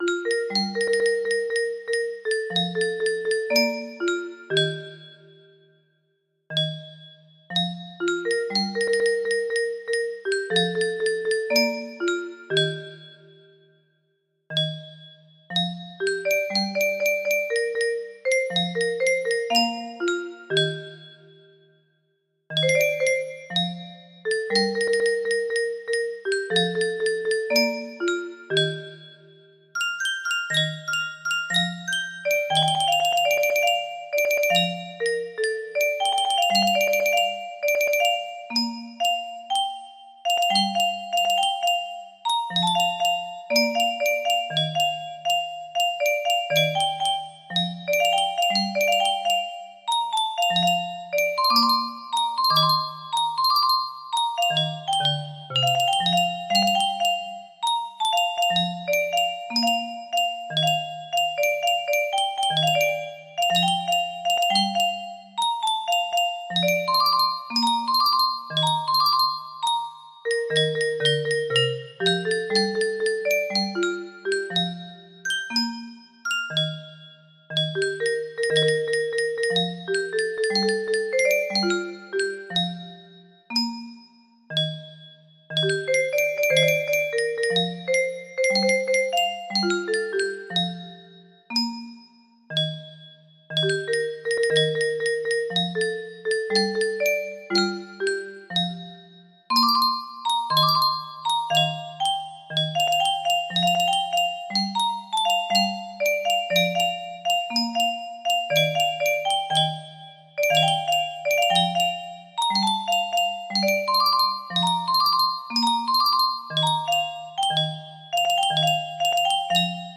Full range 60
Imported from MIDI File